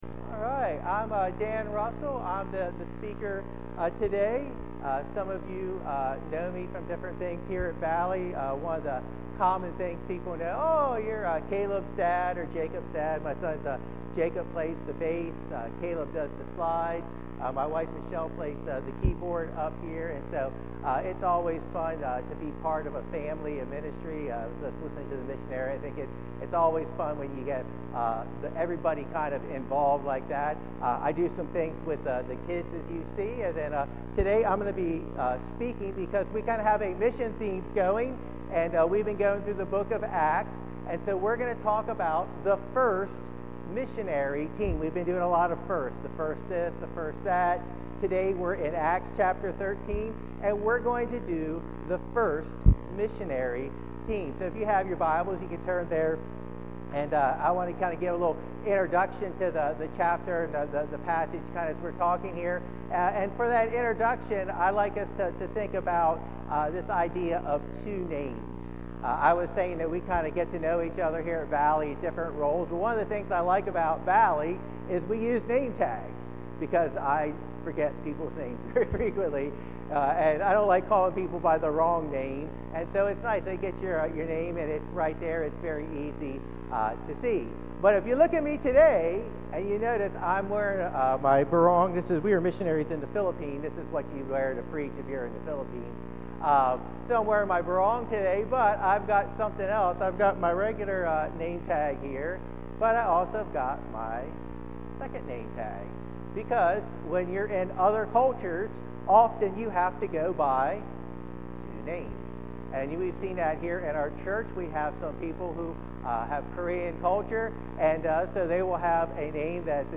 august-3-2025-sermon-only-audio.mp3